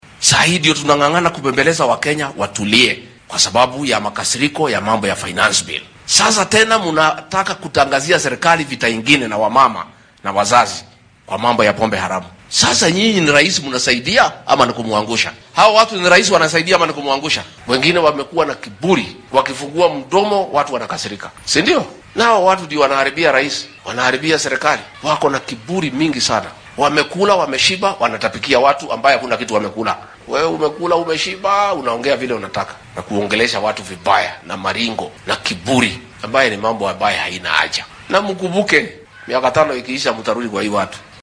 Madaxweyne ku xigeenka dalka Rigathi Gachagua ayaa sheegay in ay jiraan saraakiil ka howlgalo wasaaradda arrimaha gudaha ee waddanka oo carqaladeynaya dagaalka lagula jiro maandooriyaha. Xilli uu ku sugnaa ismaamulka Nyeri ayuu sheegay in ay tahay arrin aan la rumeysan karin in shaqaale dowladeed ay si qarsoodi ah dib ugu furayaan goobaha lagu caweeyo ee la xiray.